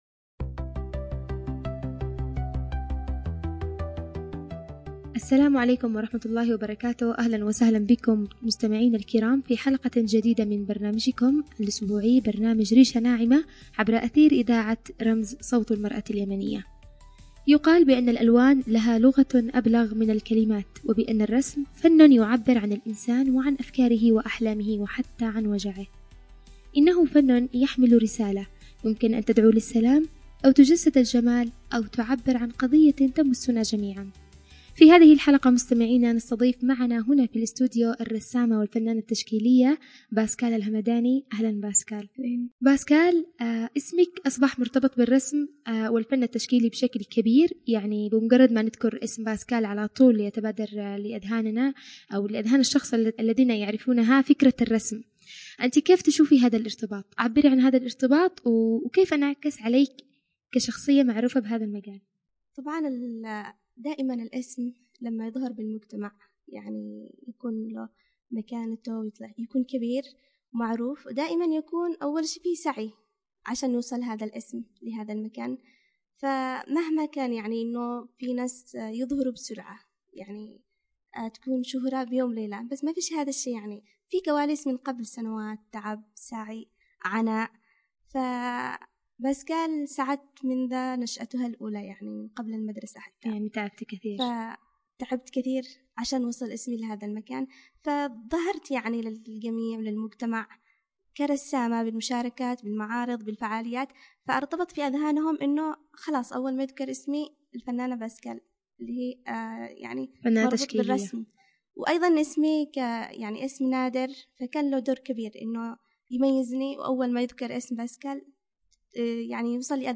في حوار حول دور الفن التشكيلي في حياة المرأة